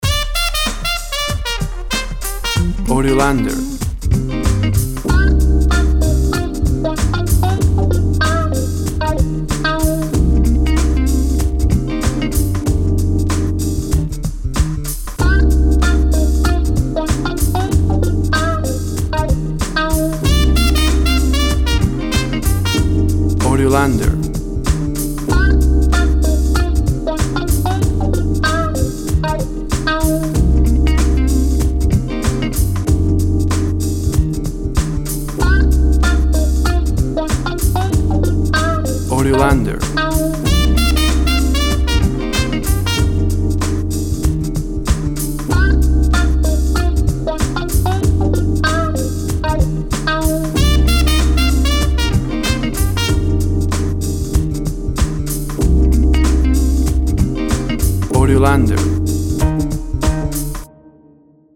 Tempo (BPM) 95